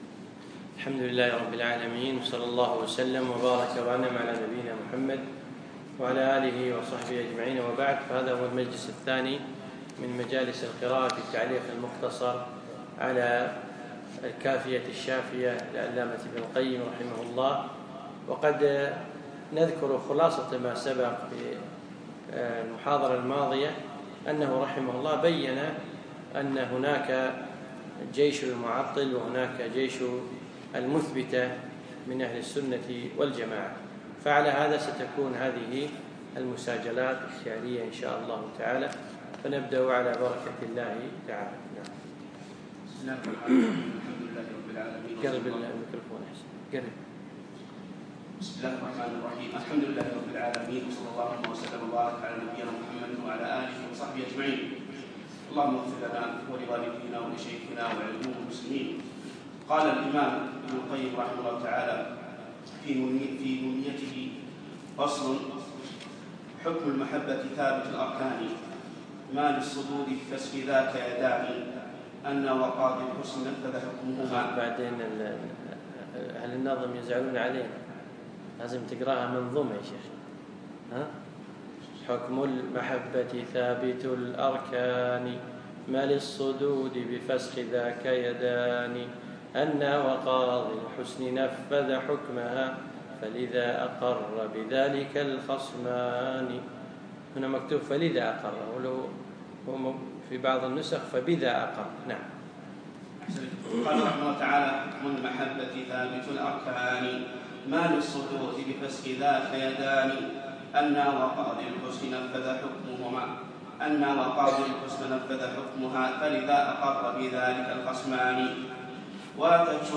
يوم الأحد 25 جمادى الأخر 1437 الموافق 3 4 2016 في مسجد أحمد العجيل القصور
الدرس الثاني